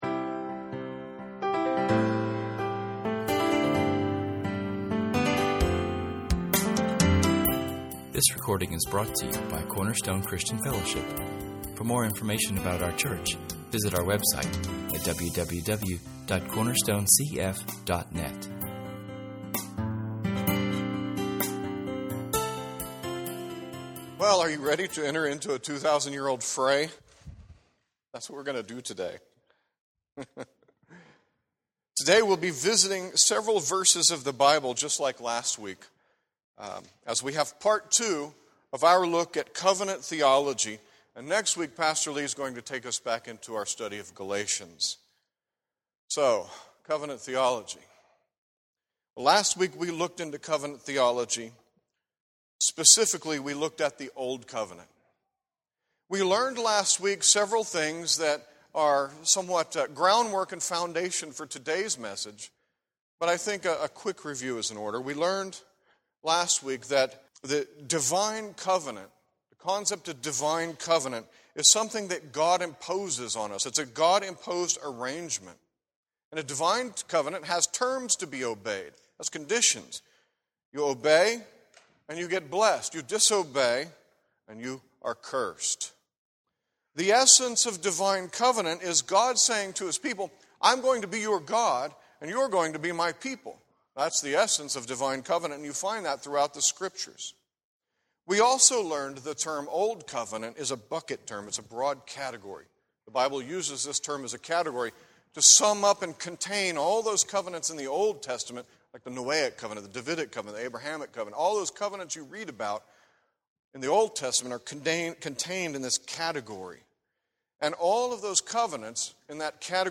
In this second of two sermons on Covenant Theology, we discover why the New Covenant is so very new, why it is better than the old, and how that newness should be practiced in the life of the church.